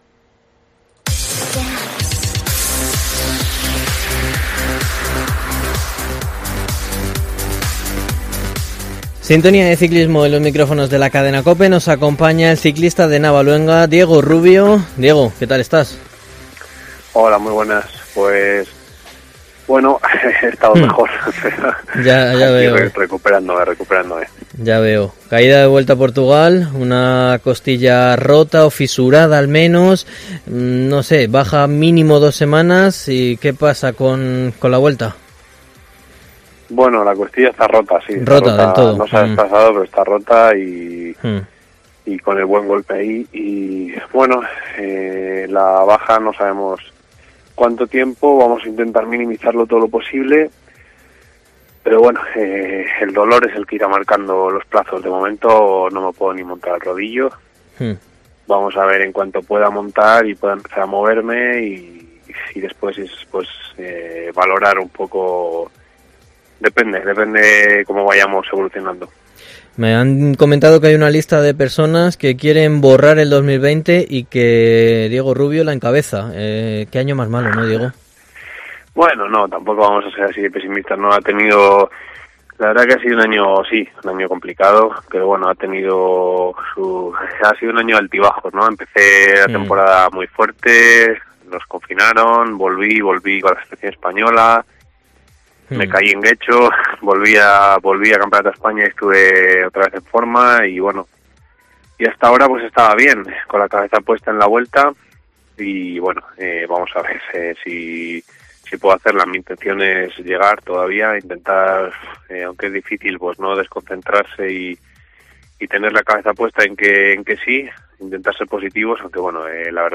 En Deportes COPE Ávila hablamos con el ciclista